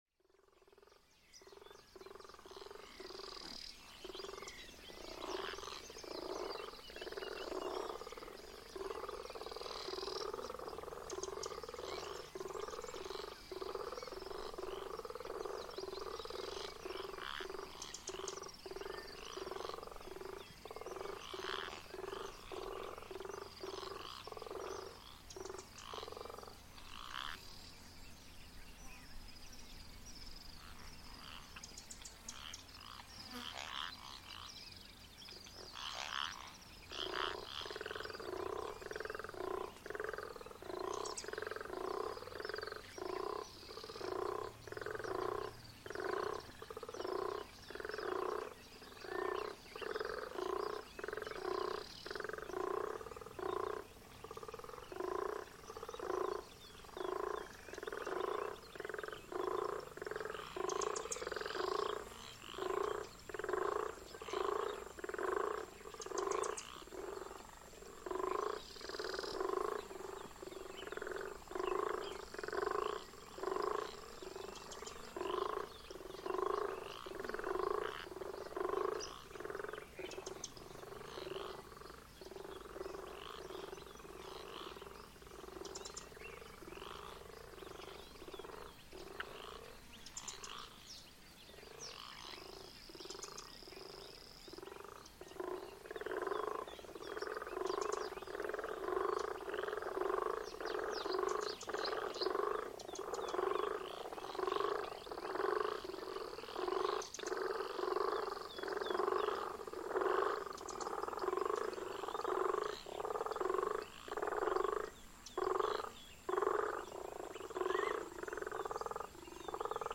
Amphibians at dawn
This recording captures the dawn amphibian chorus in the Queen Elizabeth National Park in Uganda. The previous night, a large storm had passed over and immediately after the lake sprung to life with a magnificent (and very intense) frog/toad chorus.
The following morning, when I recorded this soundscape, the soundscape was less intense, more dispersed. Birds including Hammer Cobs and Marabou Storks were walking around eating the frogs and toads, picking them off one by one. The dominant species is the Guttural Toad, Bufo gutturalis and there is also some puddle frogs, Phrynobatrachus, typical of this part of the African continent.